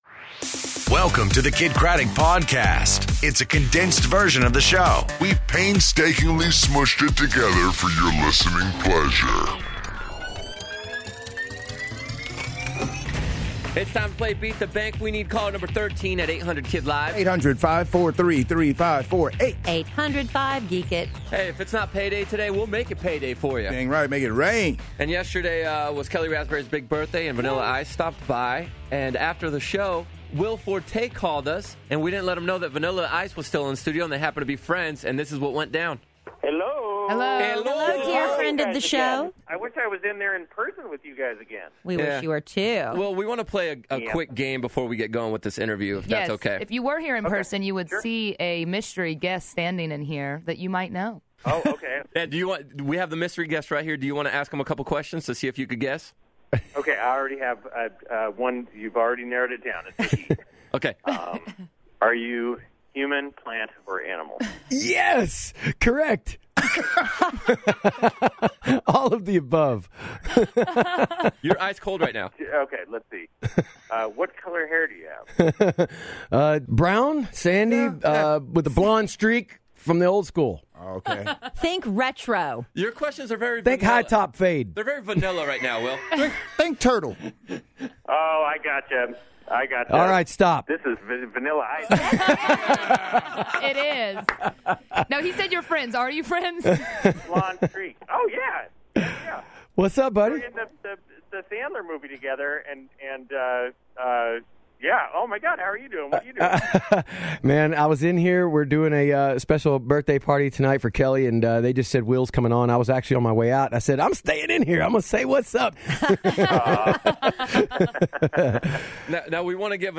A Very Special I Have A Dream, Jessie James Decker Performs In The Canalside Lounge, And I Love The 90's!